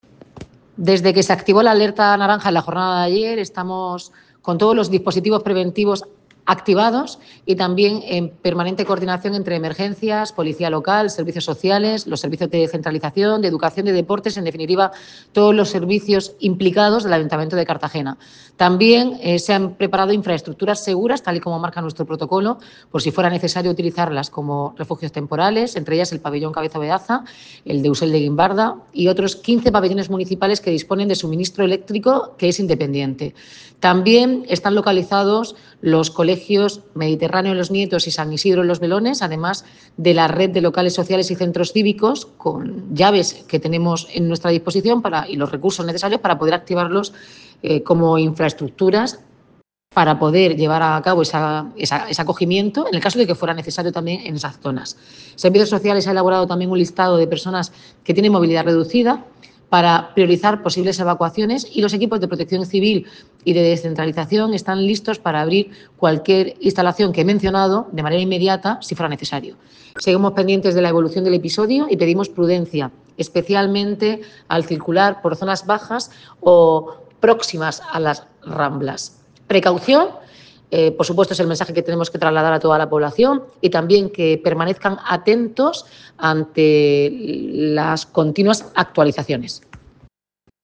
Declaraciones de la alcaldesa Noelia Arroyo.